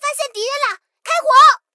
SU-76开火语音1.OGG